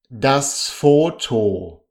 ดัส โฟ-โท